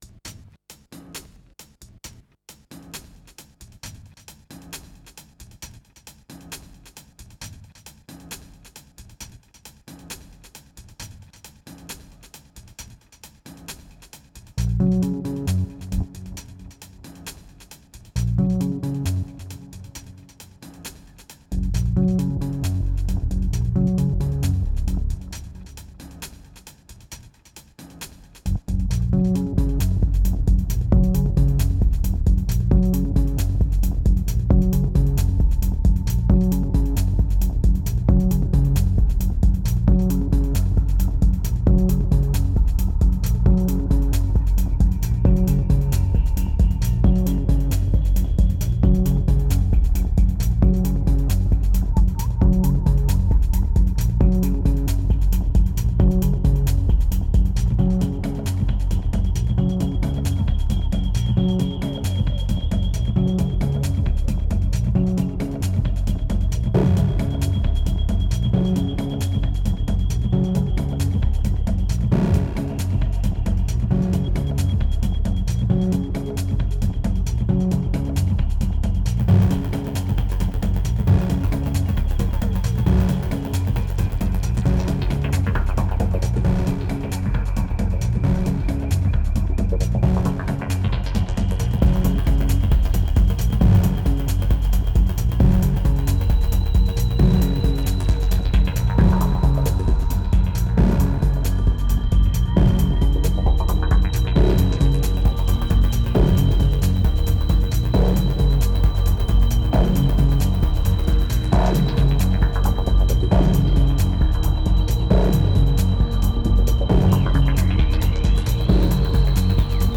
2491📈 - 10%🤔 - 134BPM🔊 - 2011-06-19📅 - -141🌟